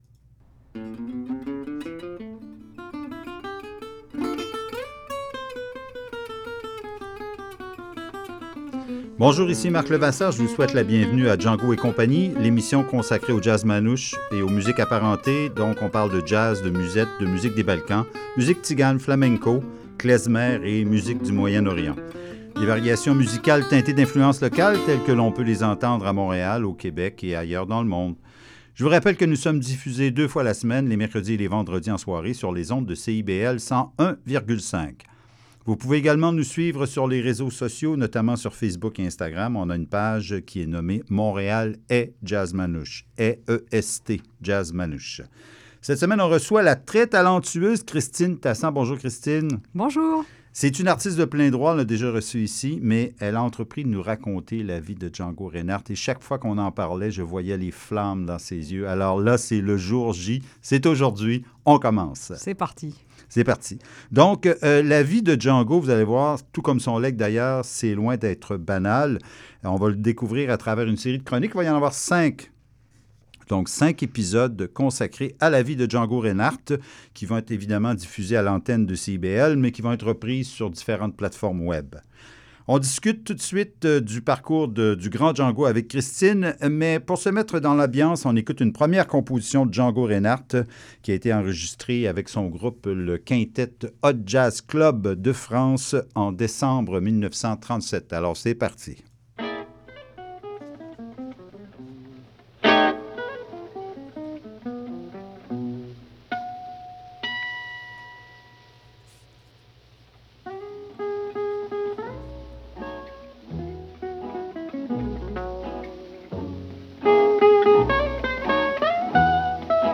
cinq épisodes radiophoniques